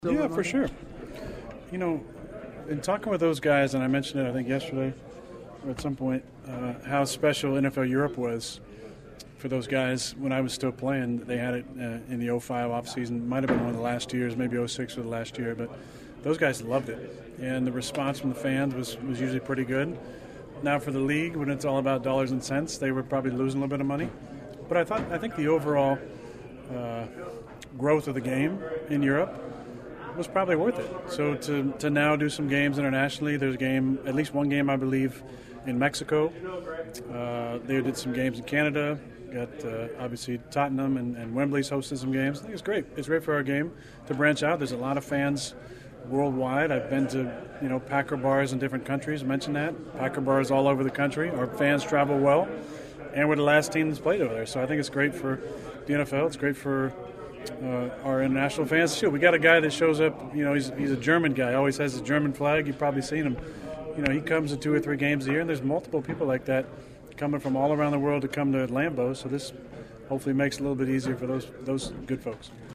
Once the locker room opened up after practice,  reporters couldn’t fire off enough questions about the trip fast enough.
Aaron Rodgers held his usual Wednesday court and said the league’s international series is still a big deal and he’s thrilled to finally be able to take part in it.